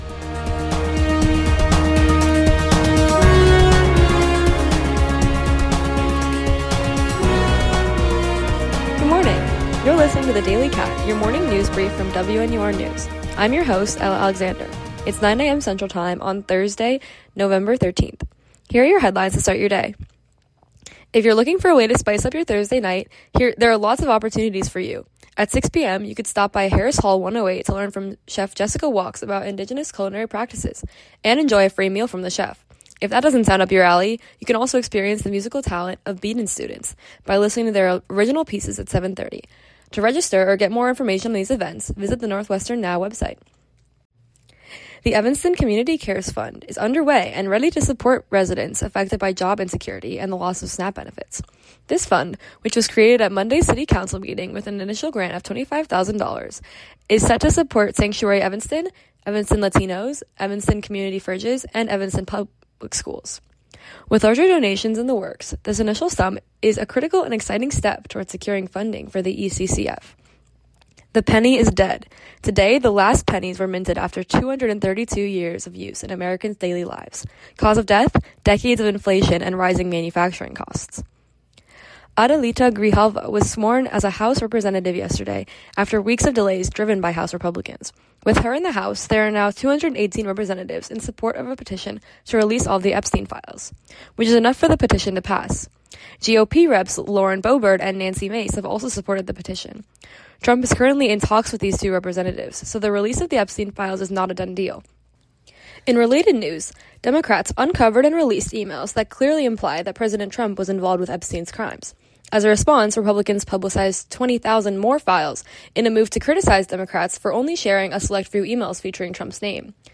November 13, 2025: Chef Talks Indigenous Cuisine, Evanston Community Cares Fund, The Death of the Penny, Updates on the Epstein Files. WNUR News broadcasts live at 6 pm CST on Mondays, Wednesdays, and Fridays on WNUR 89.3 FM.